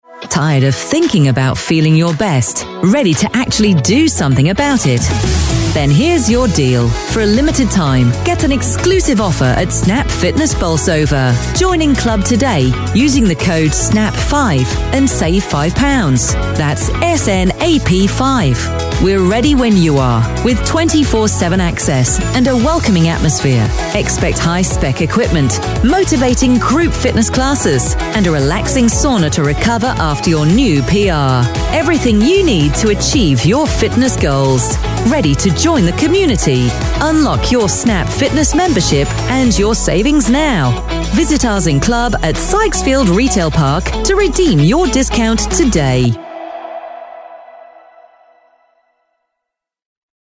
Snap-Fitness-Commercial.mp3